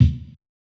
KICK GRITTY.wav